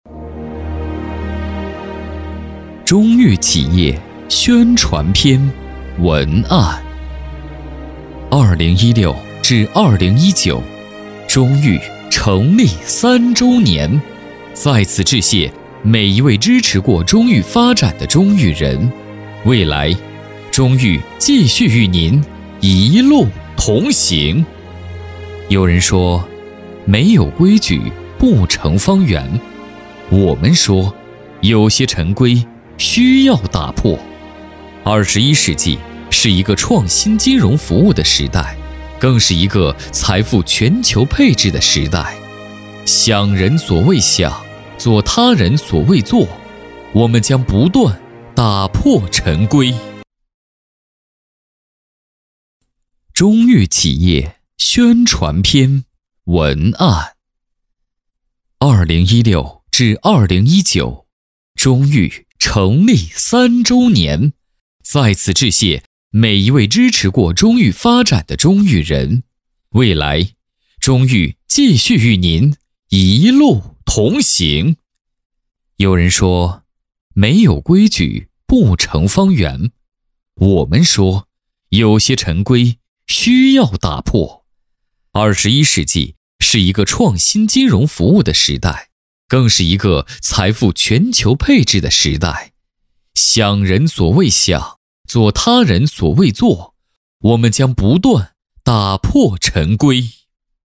擅长：专题片 广告
特点：大气浑厚 稳重磁性 激情力度 成熟厚重
风格:浑厚配音